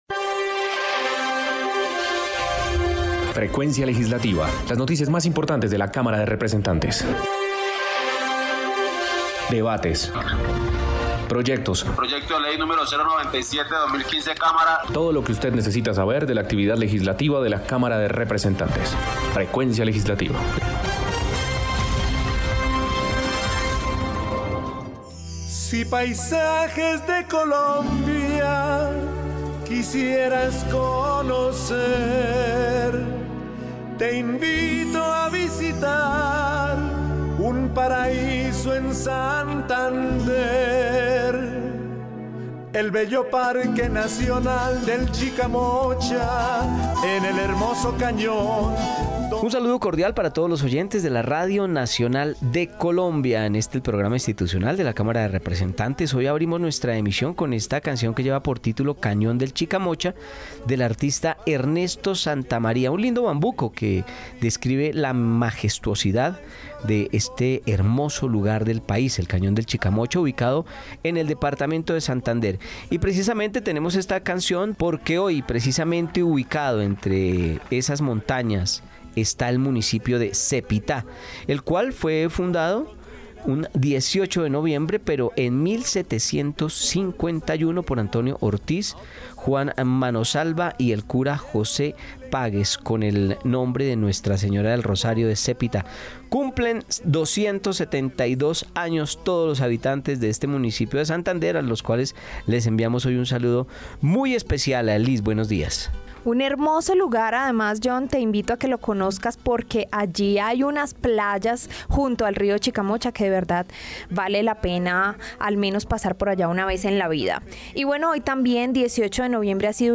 Programa Radial Frecuencia Legislativa Sábado 18 de noviembre de 2023